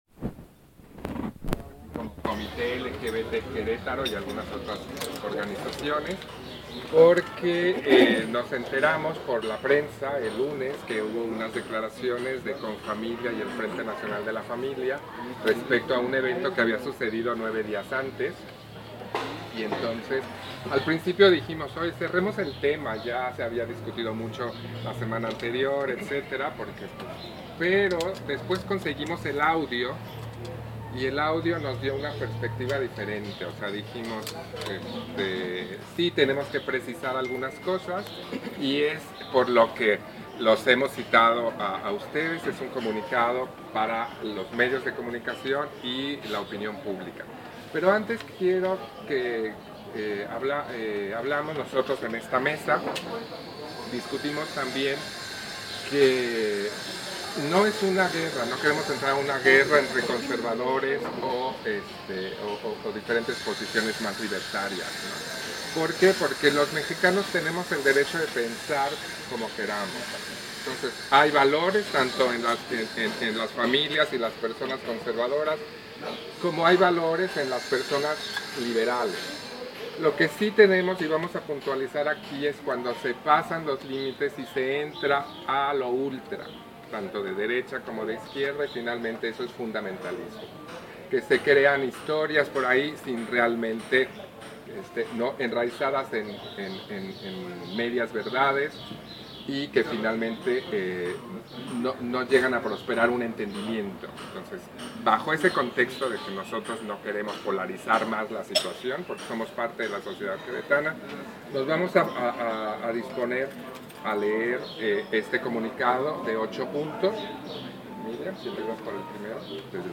Rueda de prensa de la LGBTTI I